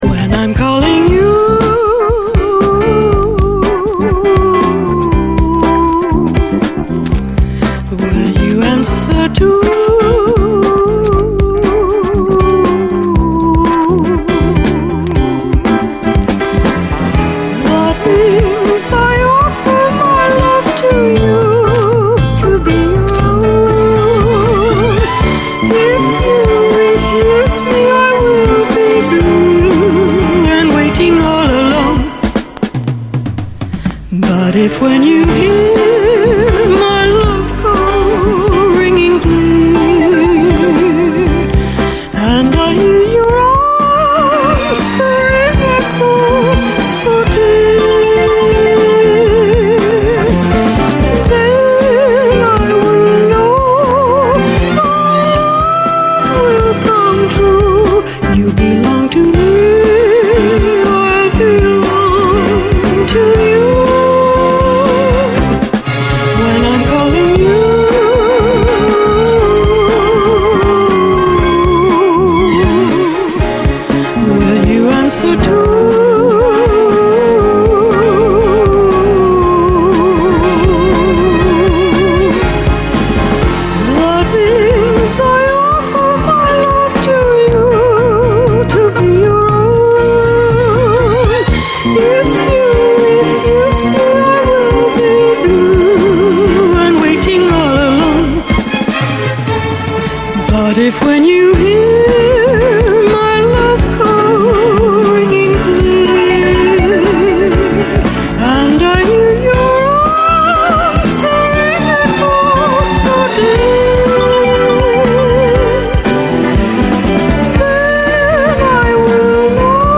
SOUL, 70's～ SOUL, 7INCH